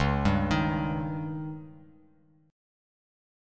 Dbsus2b5 Chord